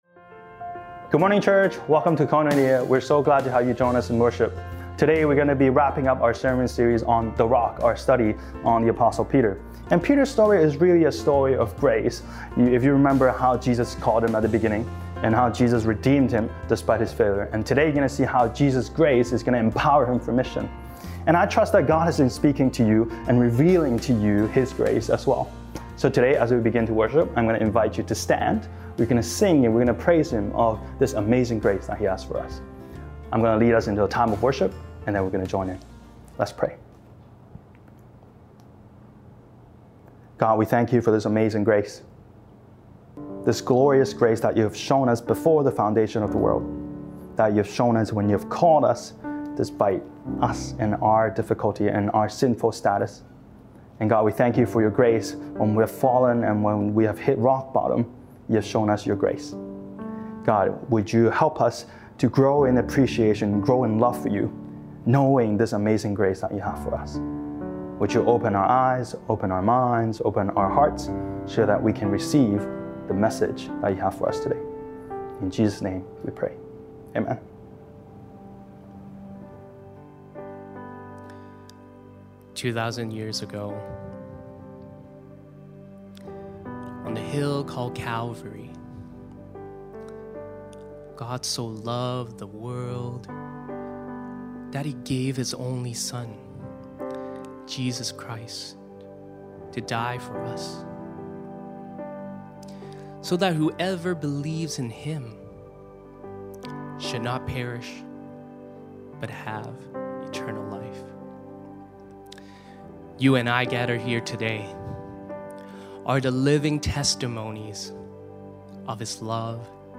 Sermons | Koinonia Evangelical Church (English - NEW duplicate)